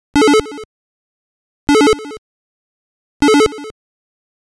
В качестве примера реализации звукового эффекта на чипе AY-3-8910 я приведу очень упрощённый вариант, который, конечно, издаёт звук, но не особо пригоден в реальной жизни, так как не предусматривает наложение эффекта на музыку.
zxsfx_ay.mp3